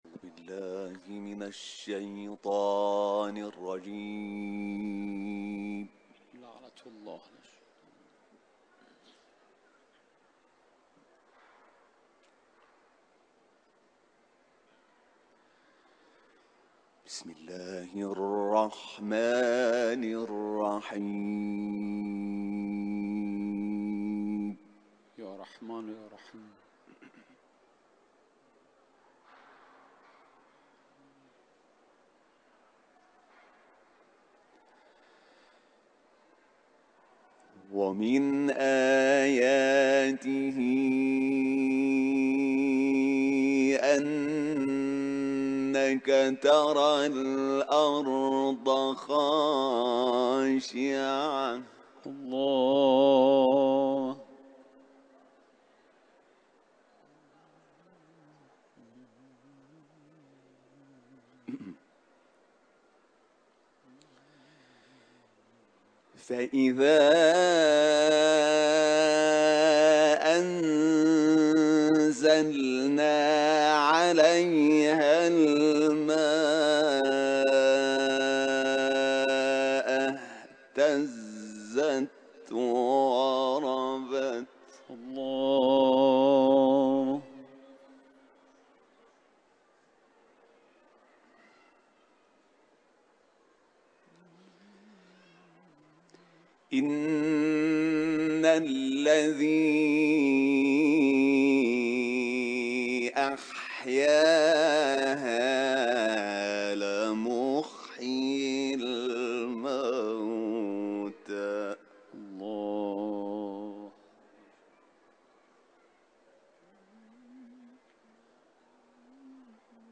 صوت تلاوت آیات 39 تا 54 از سوره «فصلت» با صدای حمید شاکرنژاد، قاری بین‌المللی قرآن که در محفل قرآنی حرم رضوی اجرا شده است، تقدیم مخاطبان ایکنا می‌شود.
تلاوت